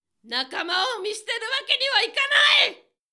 ボイス
ダウンロード 中性_「仲間を見捨てるわけにはいかない」
パワフル中性中音